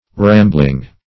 Rambling \Ram"bling\ (r[a^]m"bl[i^]ng), a.